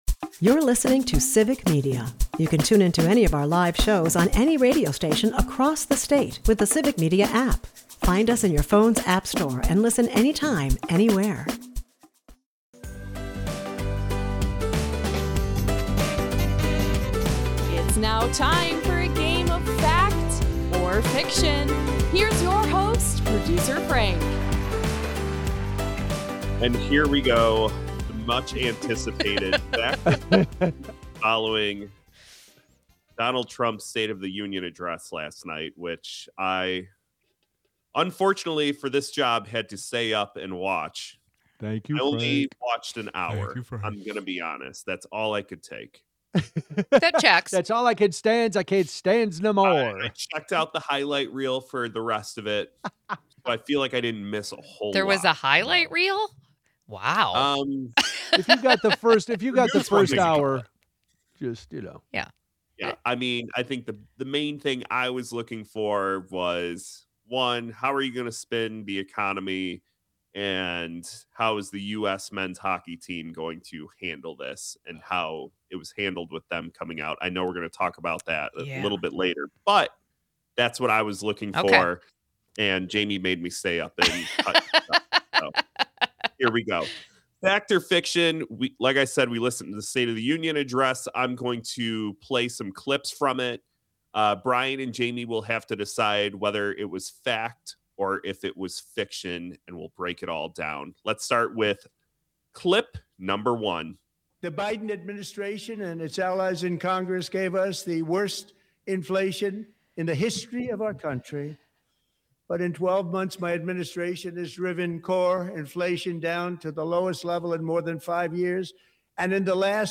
We also chat with Rep. Amaad Rivera-Wagner about legislation he is proposing to raise the minimum wage for Wisconsinites. After Rep. Rivera-Wagner gives us the details, we get a call from a veteran in Oshkosh who tells us about his struggles living on social security.